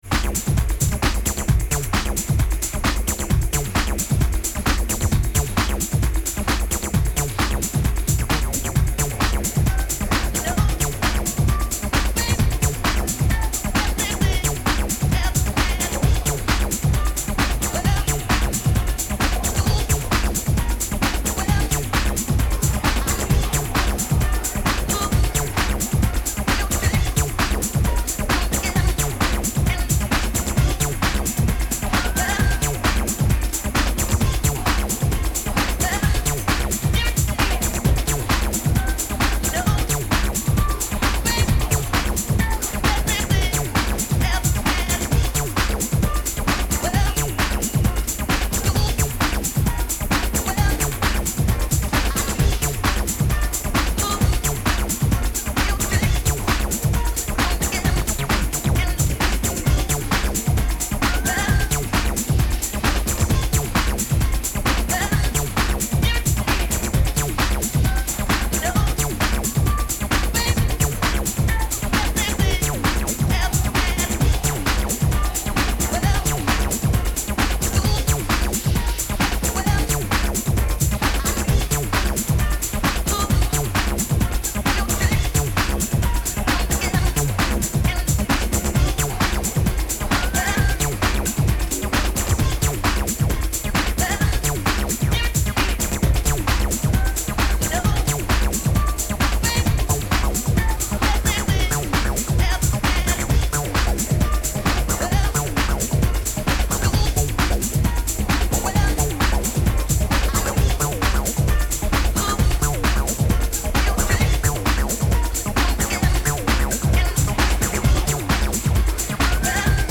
House Techno Acid